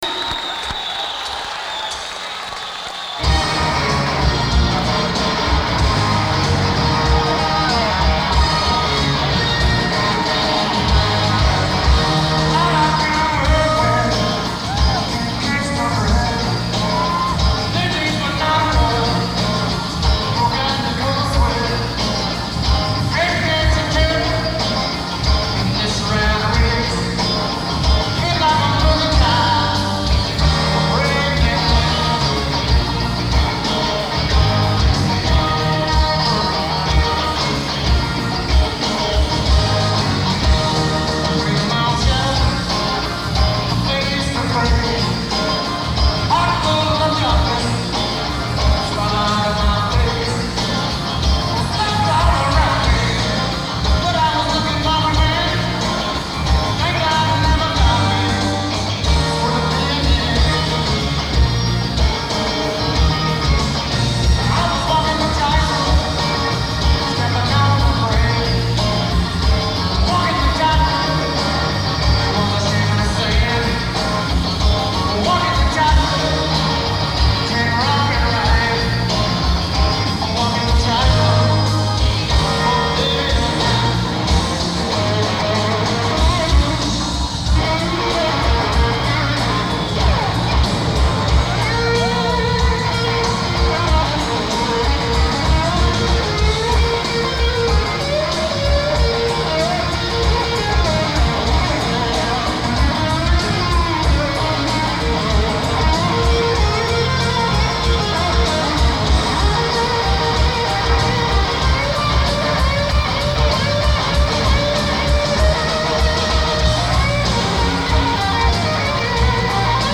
Garden State Arts Center
Holmdel, New Jersey